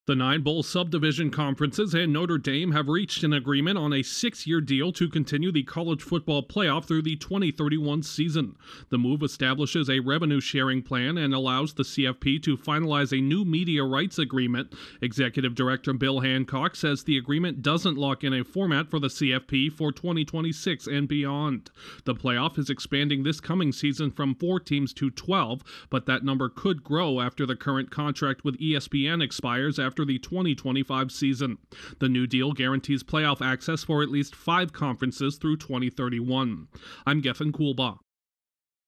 A new six-year deal has been reached to continue to College Football Playoff. Correspondent